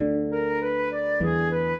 flute-harp
minuet6-7.wav